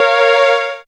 37jo01syn-a#.aif